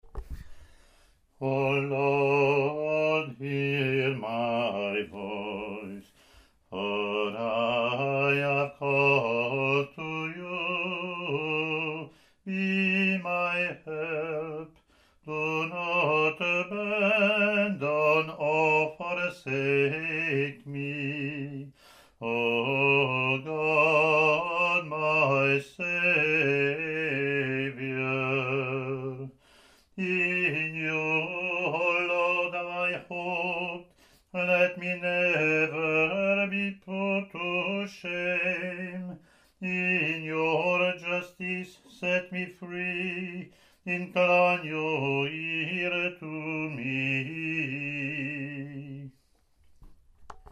English antiphon – English verse Latin antiphon and verse)